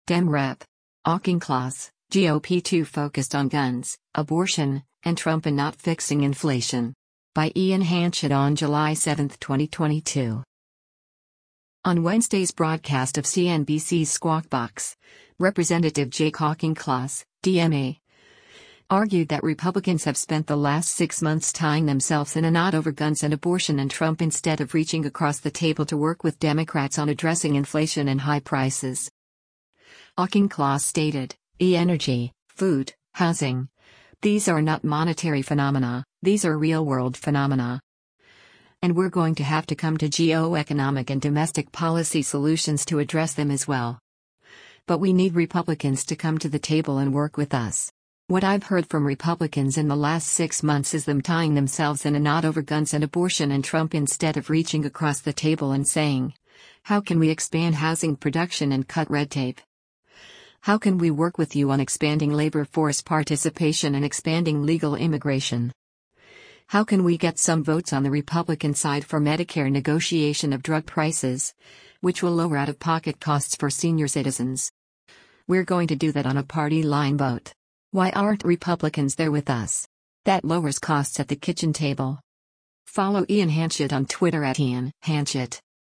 On Wednesday’s broadcast of CNBC’s “Squawk Box,” Rep. Jake Auchincloss (D-MA) argued that Republicans have spent “the last six months” “tying themselves in a knot over guns and abortion and Trump instead of reaching across the table” to work with Democrats on addressing inflation and high prices.